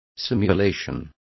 Complete with pronunciation of the translation of simulation.